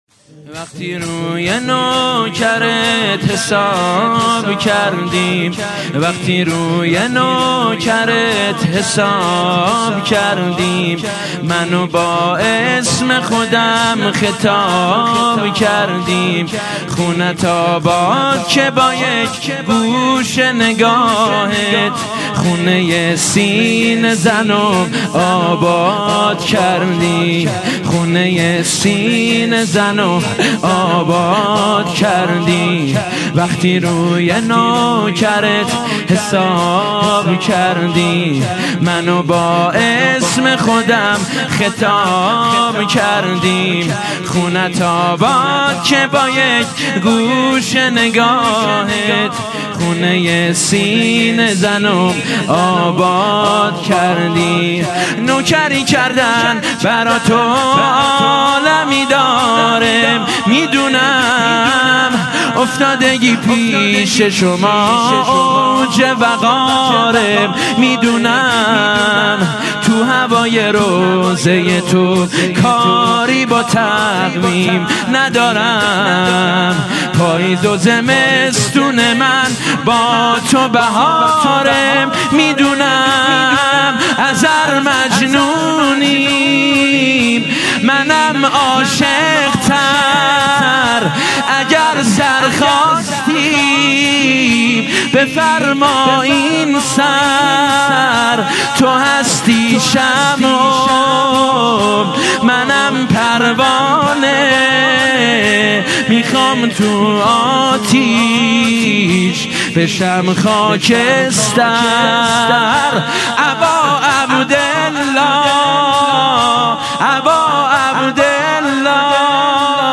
مداحی شب هفتم محرم 1399
شور - وقتي روي نوکرت حساب کردي